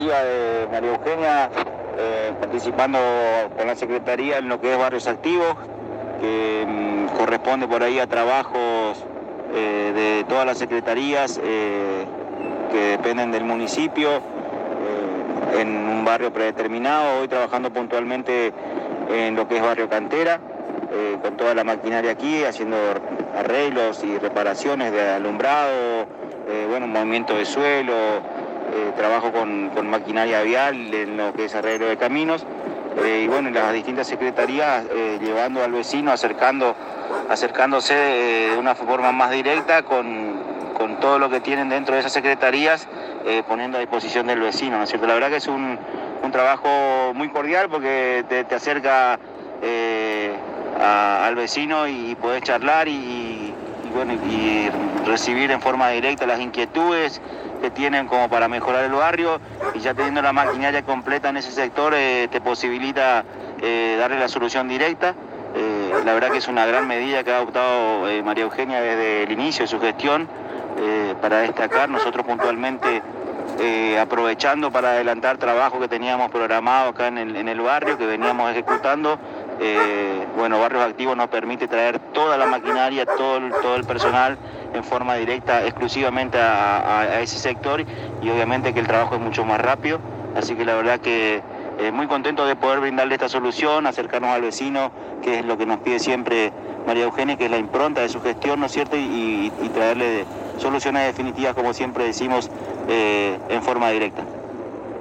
En diálogo exclusivo con la ANG el director de la Juventud Aldo Muñoz comentó que estos programas traen beneficios económicos millonarios que son volcados en la economía apostoleña brindando una gran ayuda a los Jóvenes y sus familias.